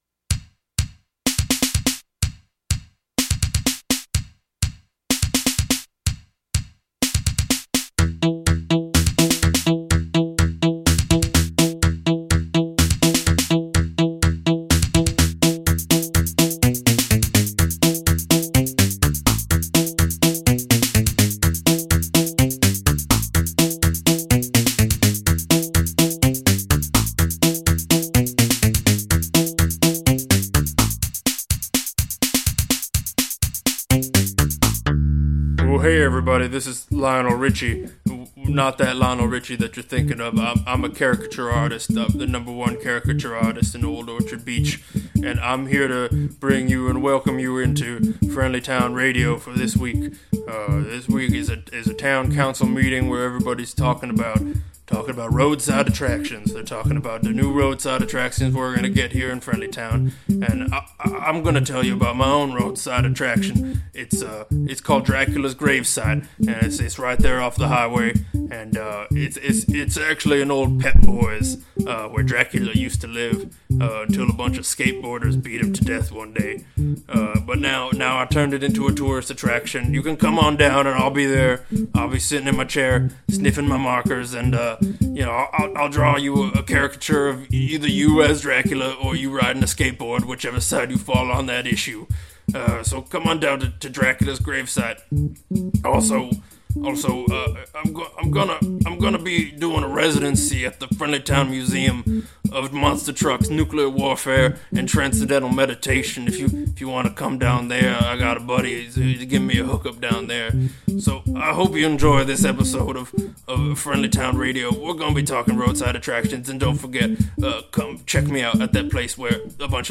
| sonic comedy
On this month's episode, we invite comics from all over to present their ideas for that HOT museum, monument, or other eyesore to bring those tourist dollars into our town.